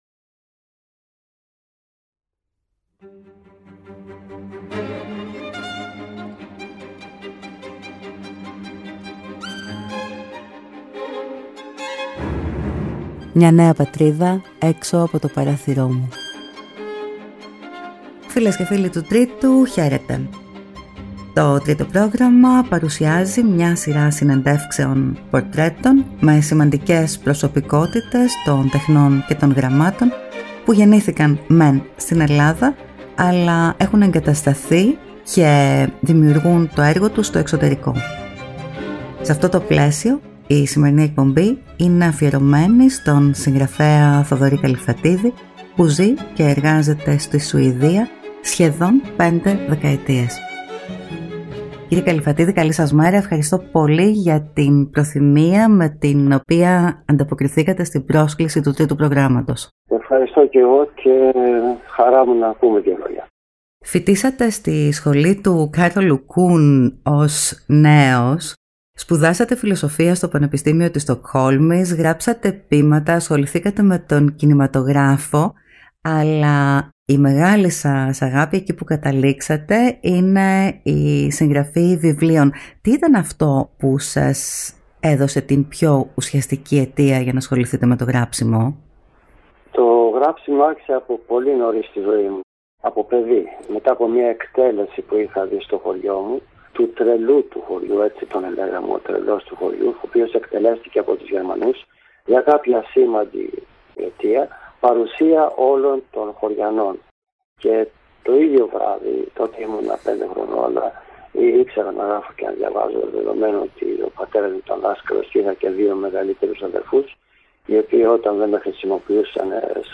Μια σειρά συνεντεύξεων με σημαντικούς έλληνες των τεχνών και των γραμμάτων, που ζουν και δημιουργούν τα έργα τους στο εξωτερικό και που συνθέτουν ένα φωτεινό και αισιόδοξο πρόσωπο της Ελλάδας.